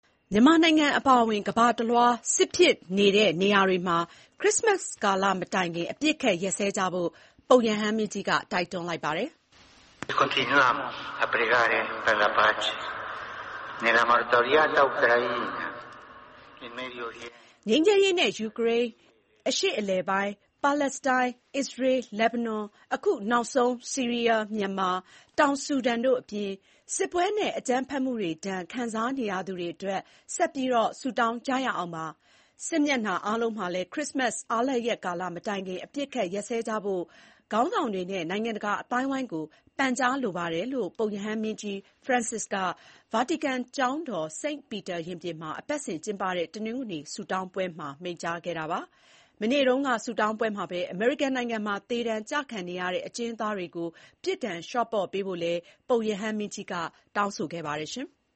ဗာတီကန် တနင်္ဂနွေနေ့ ဝတ်ပြုဆုတောင်းပွဲမှာ မြန်မာအပါအဝင် စစ်ဖြစ်နေတဲ့နိုင်ငံတွေမှာ ခရစ္စမတ်မတိုင်ခင် အပစ်ရပ်ဖို့ ပုပ်ရဟန်းမင်းတိုက်တွန်း။ (ဒီဇင်ဘာ ၈၊ ၂၀၂၄)